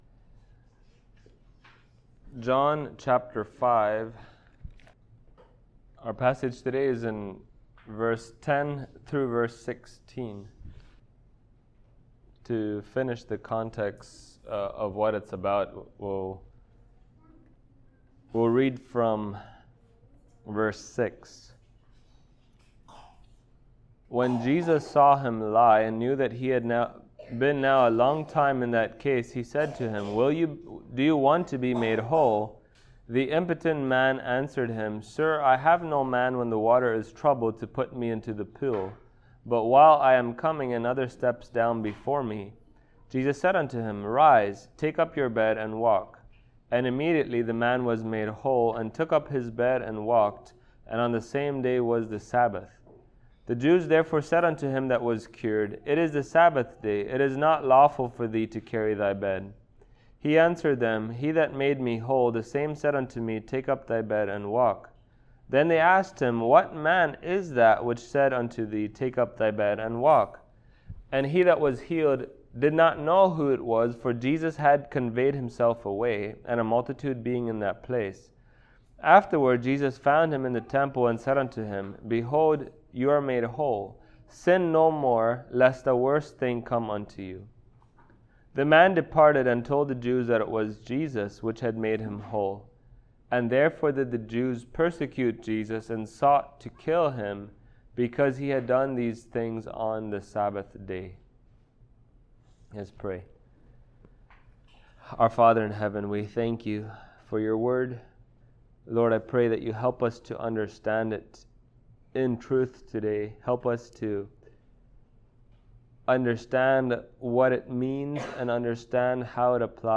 John Passage: John 5:10-16 Service Type: Sunday Morning Topics